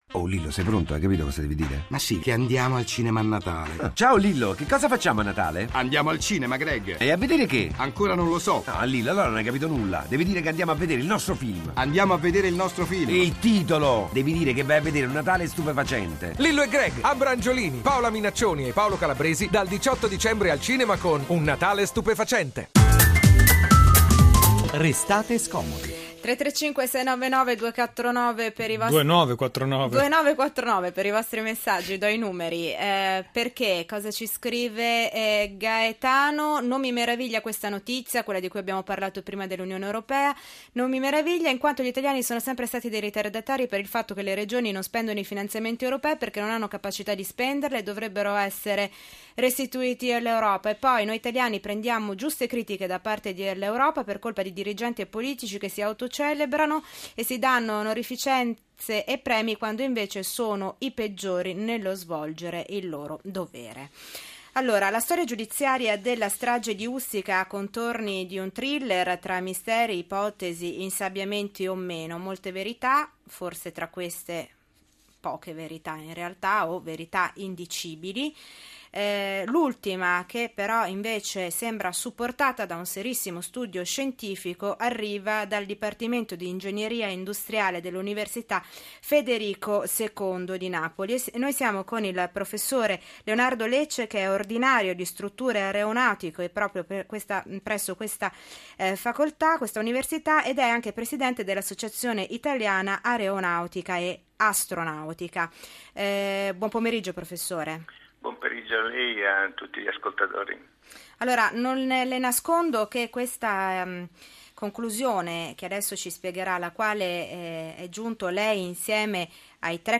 intervista radio 1